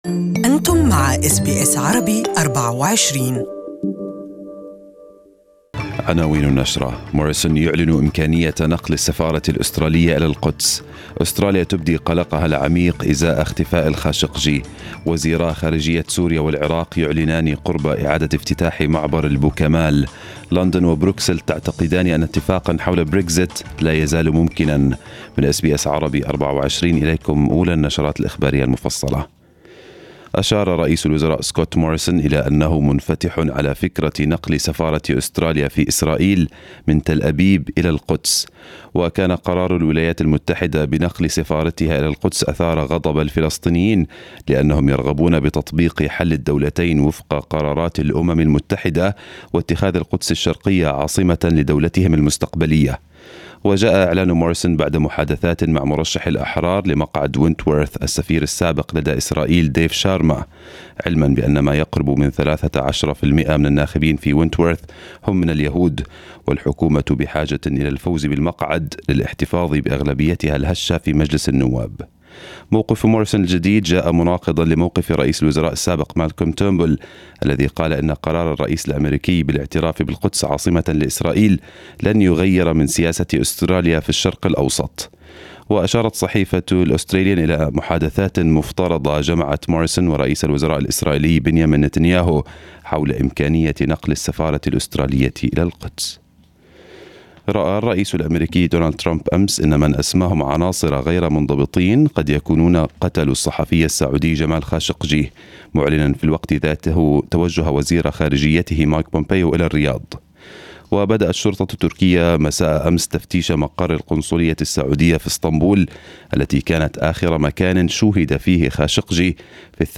News bulletin of the morning